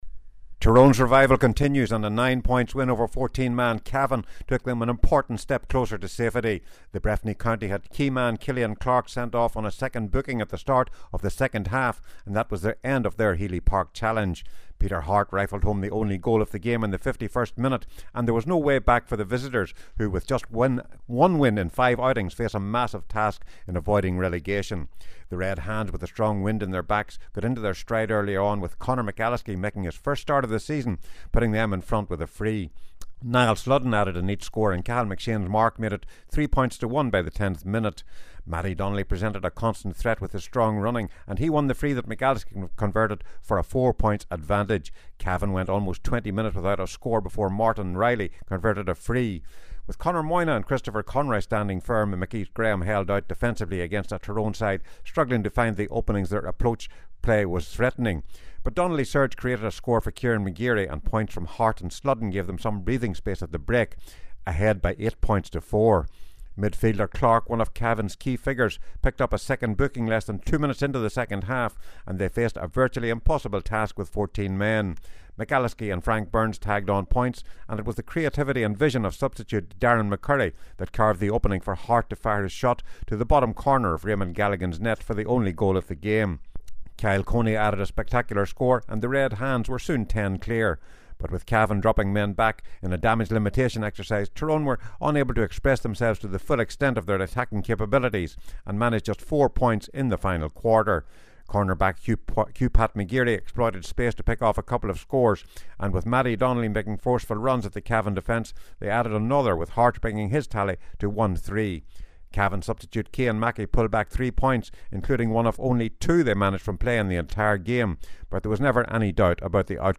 Tyrone beat Cavan to move up to 4th in Division 1 – FT Report & Reaction